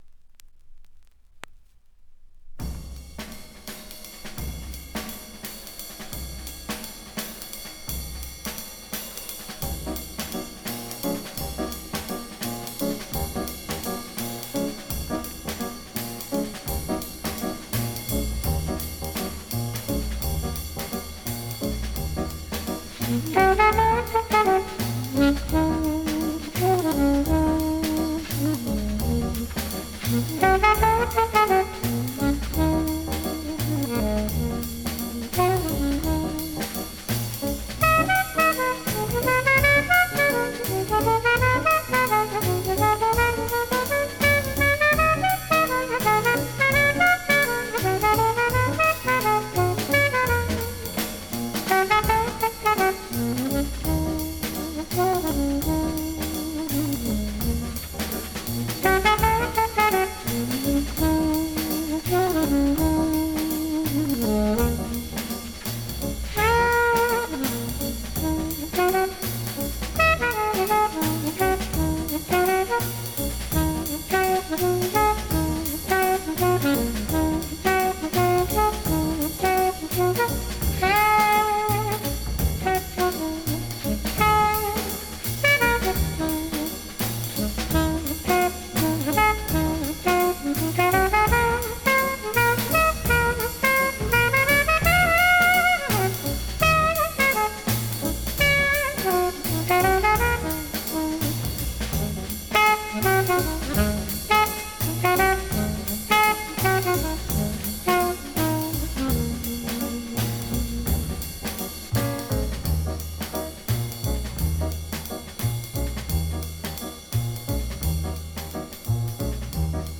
a jazz pianist who had unparalleled commercial success
alto saxophonist
instrumental hit
Rega P25 plays
on vinyl